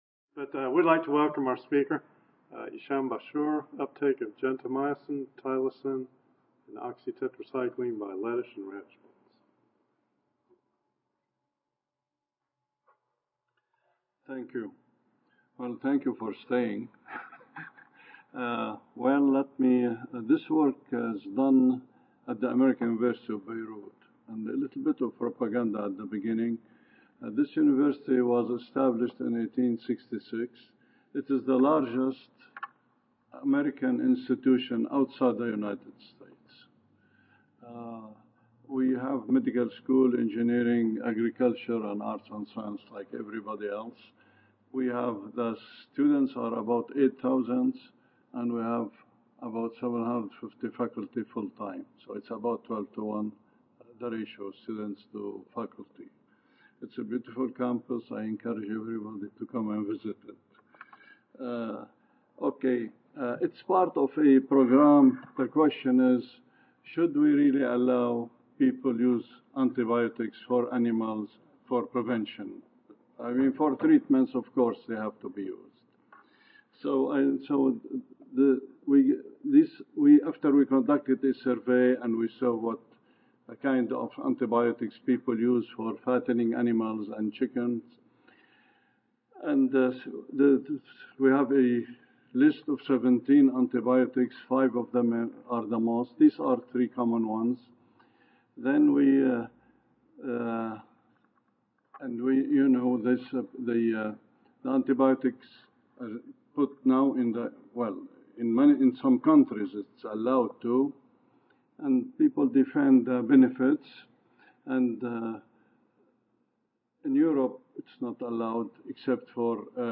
American University of Beirut Audio File Recorded Presentation